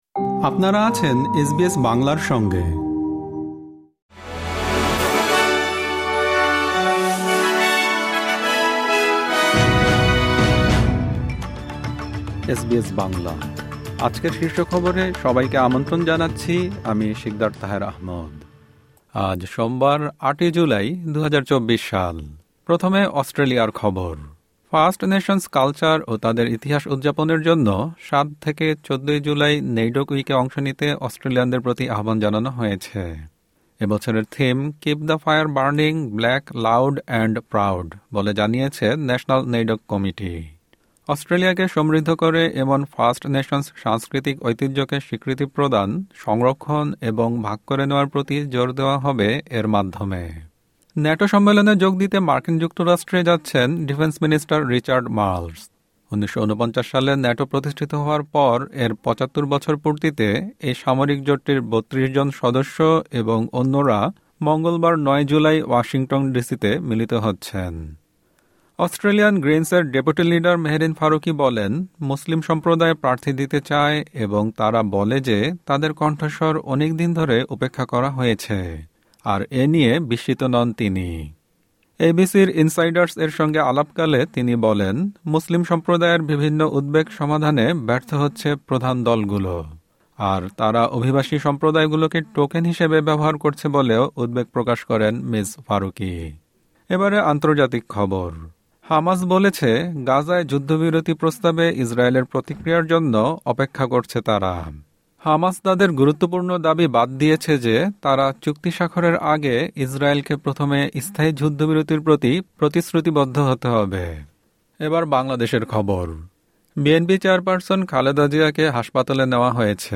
এসবিএস বাংলা শীর্ষ খবর: ৮ জুলাই, ২০২৪